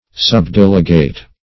Search Result for " subdelegate" : The Collaborative International Dictionary of English v.0.48: Subdelegate \Sub*del"e*gate\, n. A subordinate delegate, or one with inferior powers.